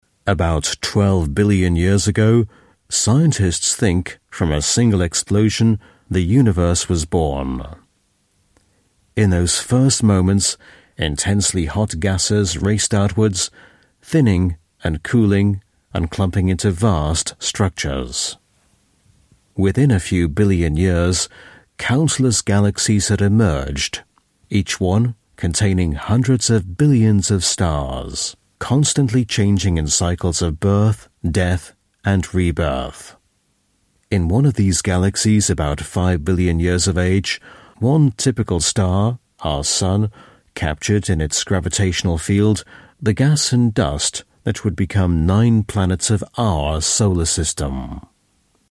Dokus
Native Speaker